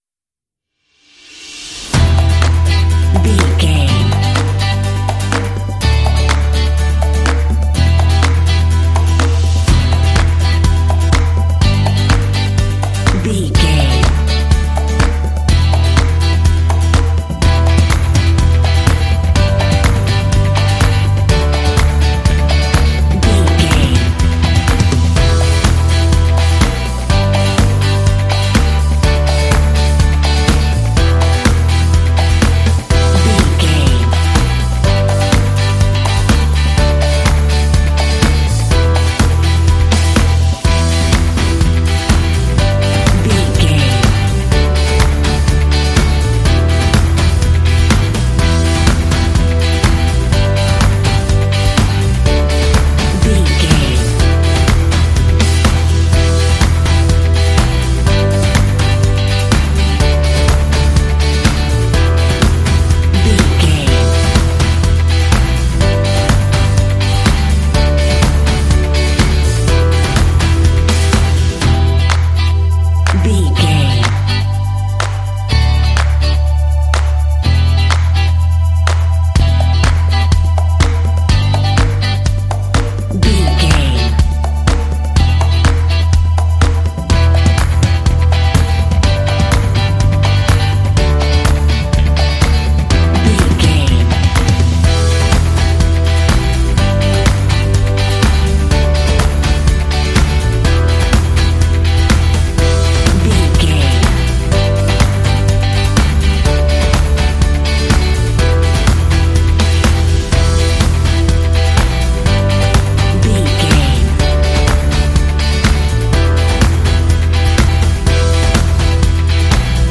Uplifting
Aeolian/Minor
lively
cheerful
electric guitar
bass guitar
strings
drums
percussion
synth-pop
rock
indie